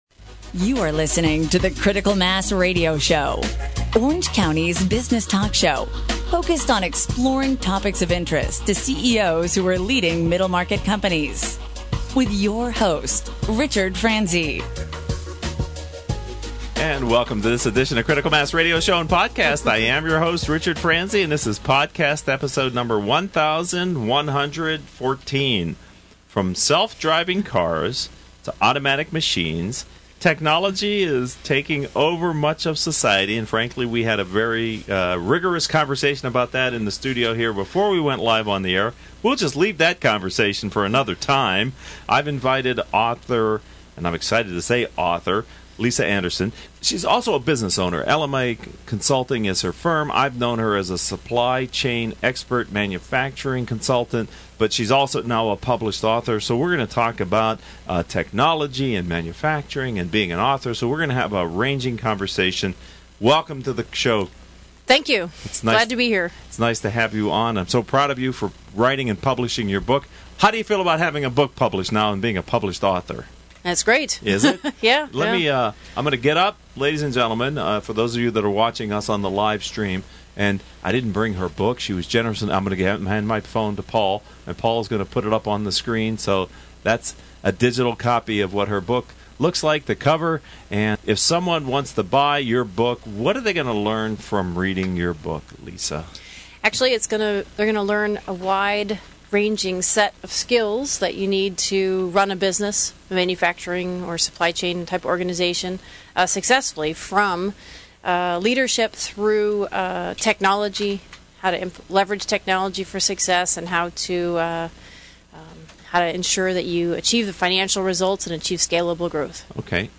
Critical Mass Radio Show: Interview on Will Robots Pay Back?